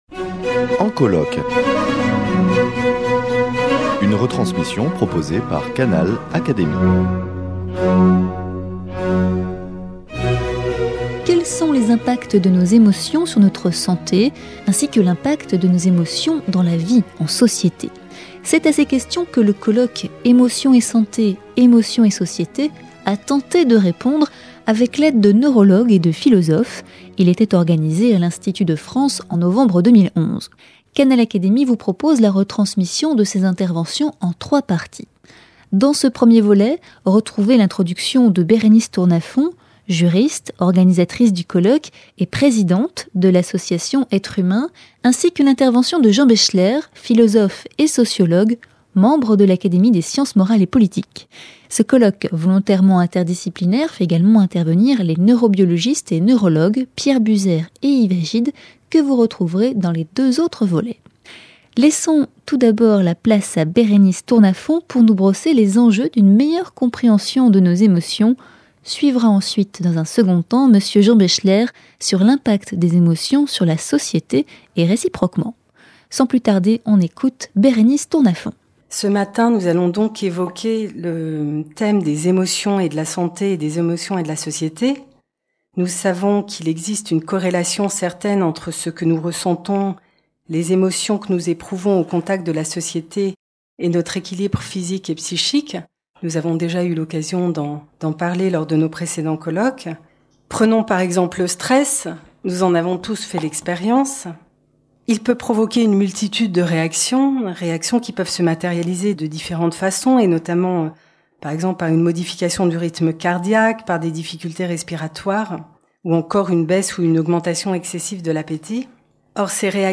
Canal Académie vous propose la retransmission en trois parties du colloque Émotions et santé, émotions et société organisé à l'Institut de France en novembre 2011.